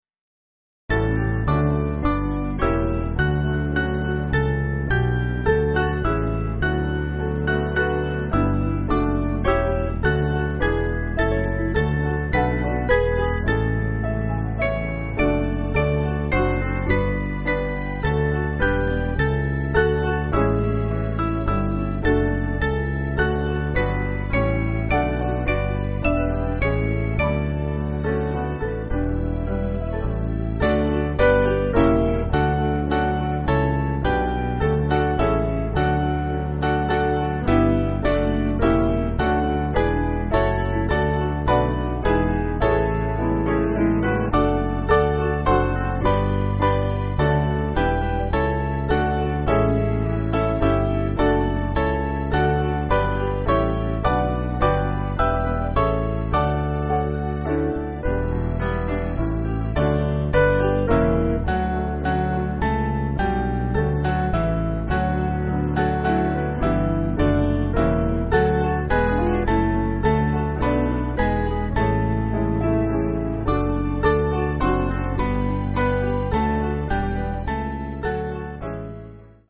Hymn books
Mainly Piano